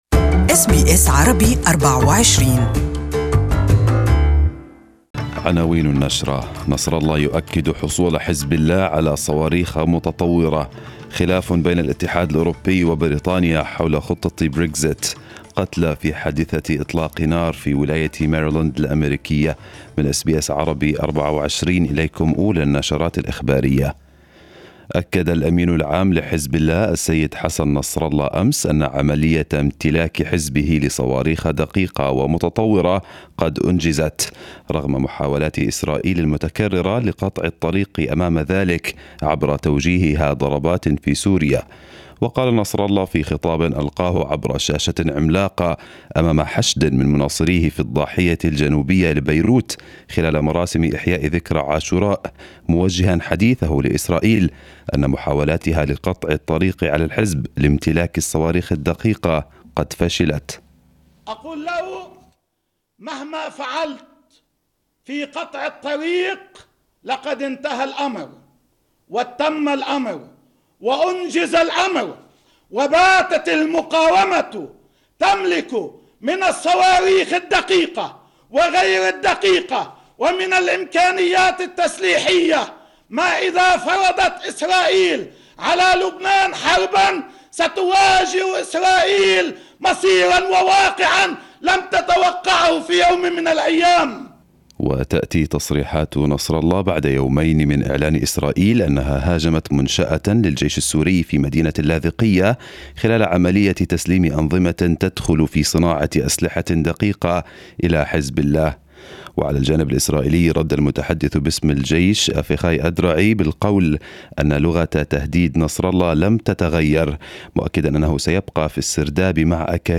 First news Bulletin in the morning